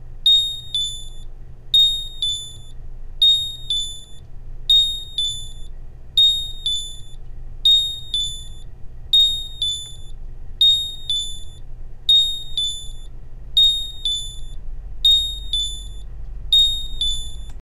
チャイム音　サンプル
低速警報チャイム 高速警報チャイム